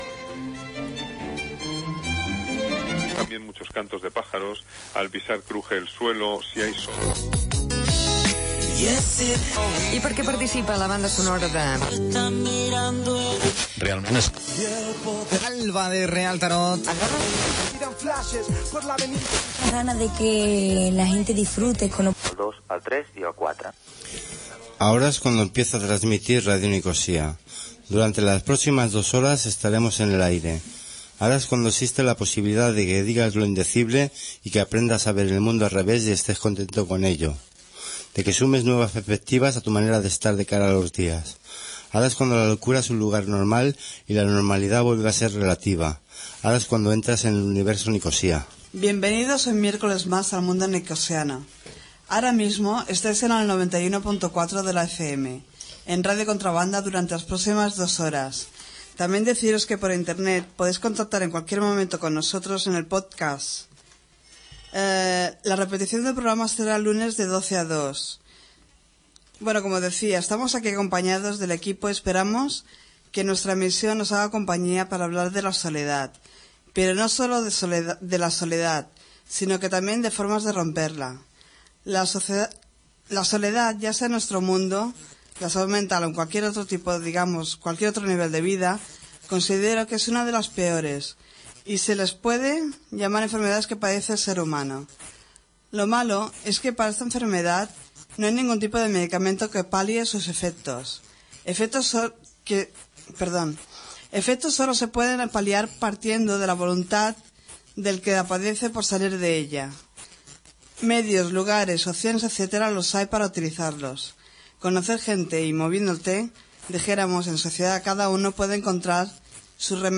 Inici del programa.